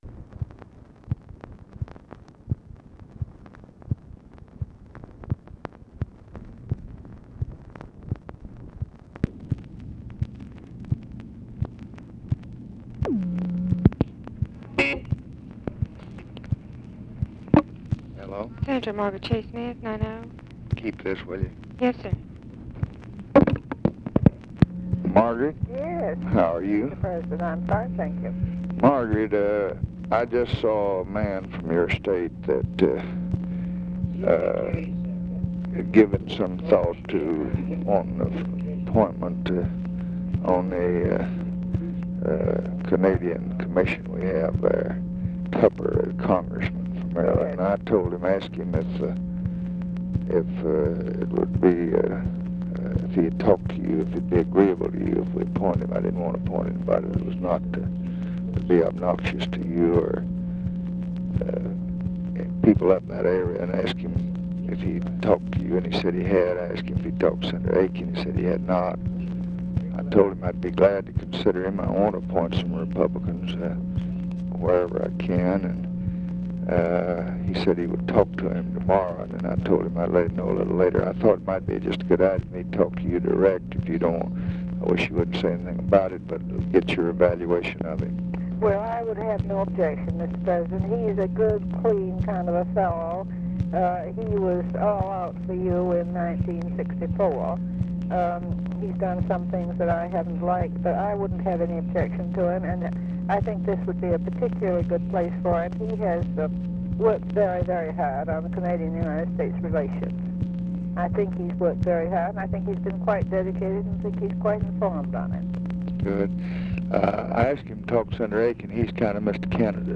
Conversation with MARGARET CHASE SMITH, LADY BIRD JOHNSON and OFFICE CONVERSATION, March 3, 1966
Secret White House Tapes